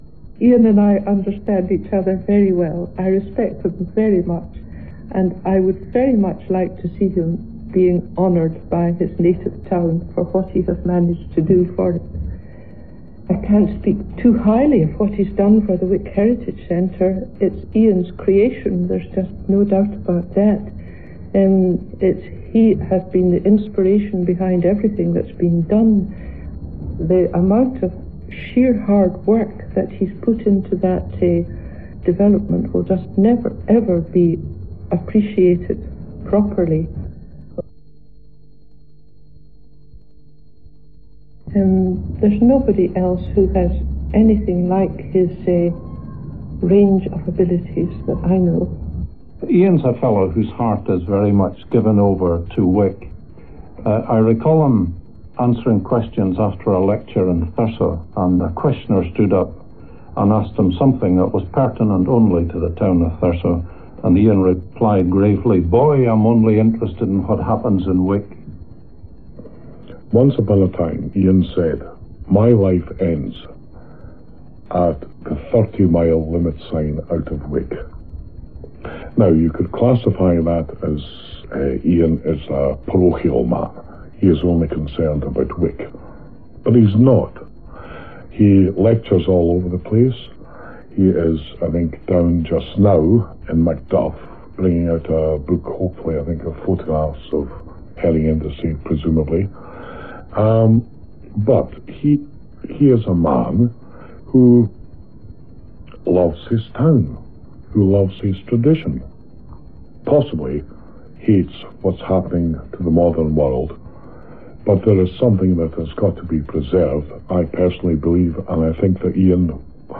Edited-Part-2-Radio-Interview.mp3